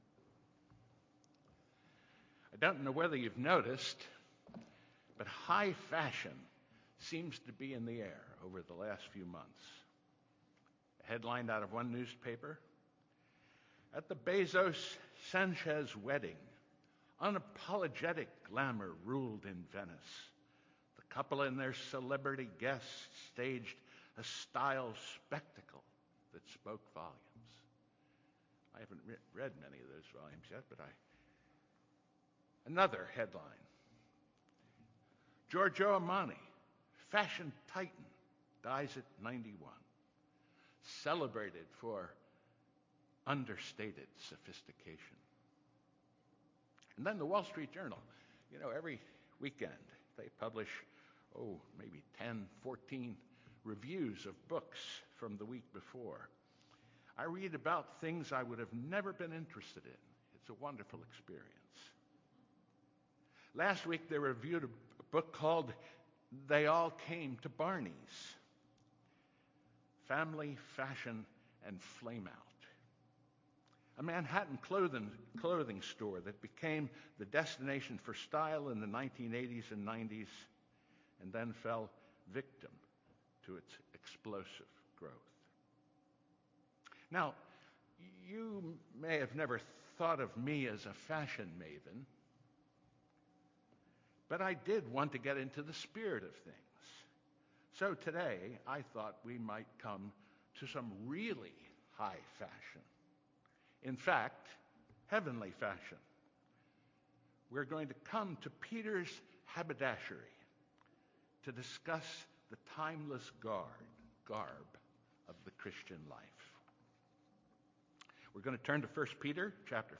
The Garb of Humility: Sermon on 1Peter 5:1-7 - New Hope Presbyterian Church